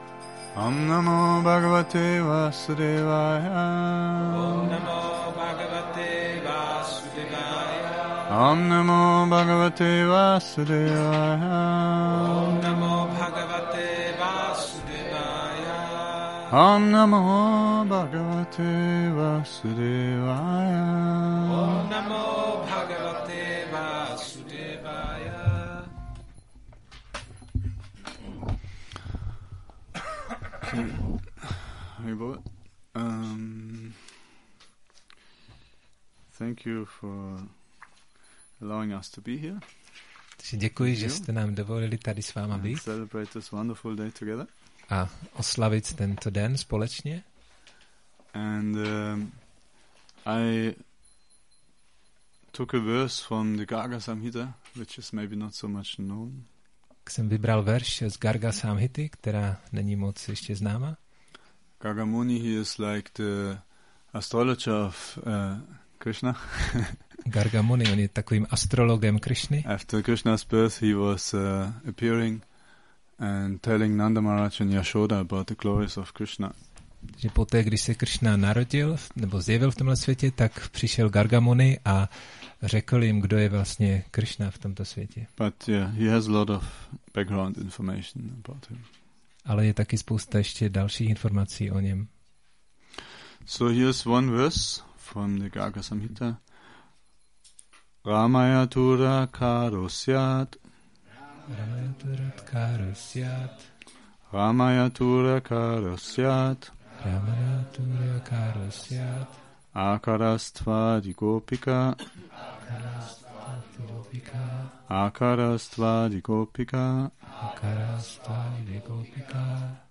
Přednáška Šrí Rádháštamí